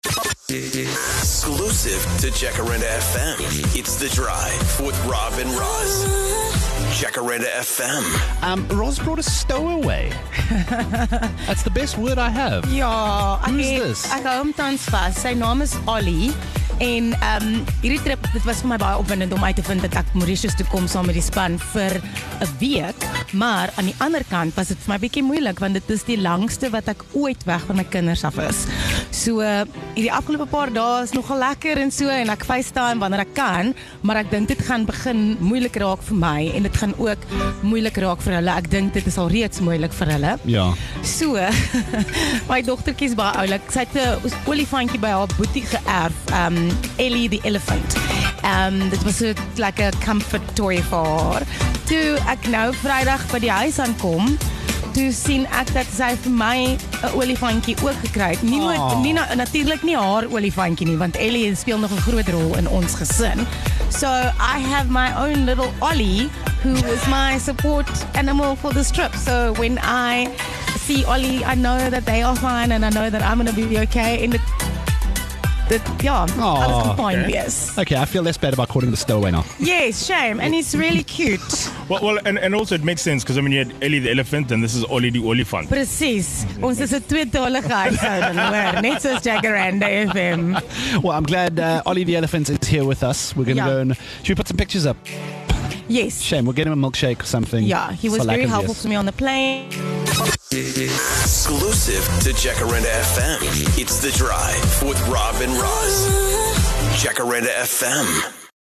This week the team is broadcasting all the way from sunny Mauritius! As it turns out some of them are missing home more than others.